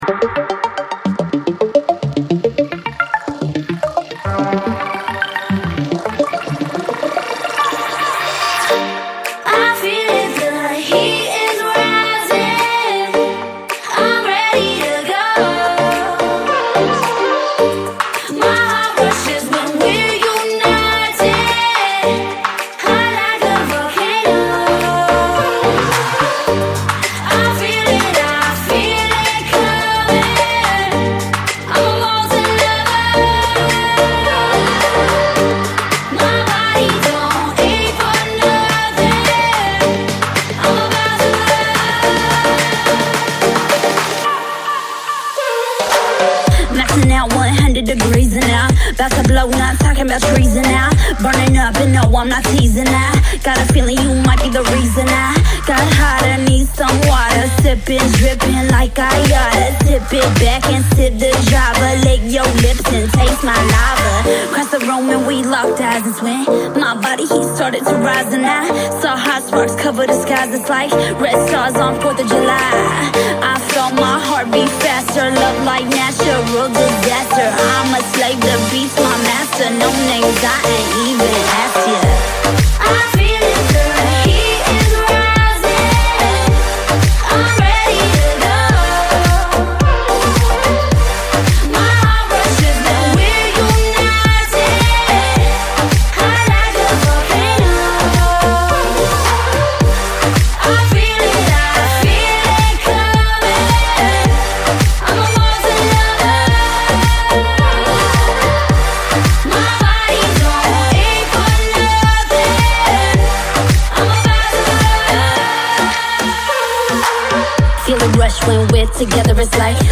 энергичная и провокационная песня в жанре поп-рэп